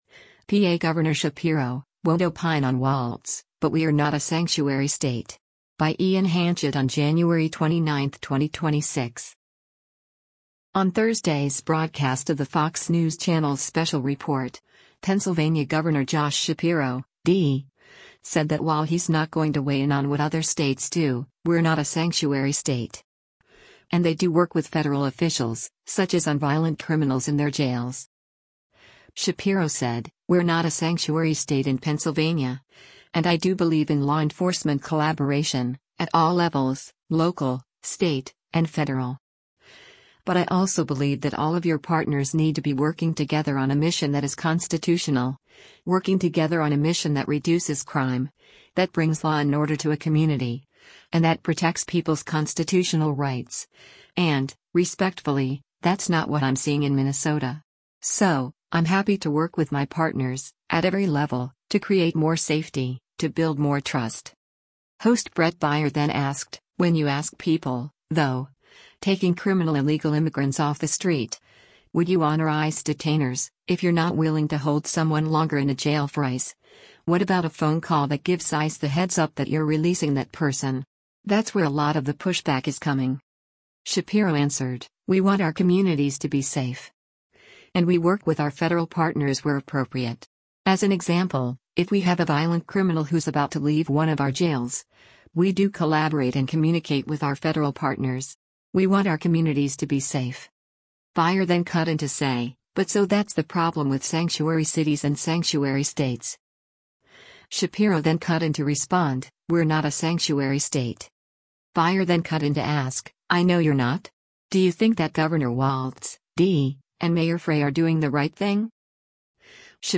On Thursday’s broadcast of the Fox News Channel’s “Special Report,” Pennsylvania Gov. Josh Shapiro (D) said that while he’s not going to weigh in on what other states do, “We’re not a sanctuary state.”
Host Bret Baier then asked, “When you ask people, though, taking criminal illegal immigrants off the street, would you honor ICE detainers, if you’re not willing to hold someone longer in a jail for ICE, what about a phone call that gives ICE the heads-up that you’re releasing that person? That’s where a lot of the pushback is coming.”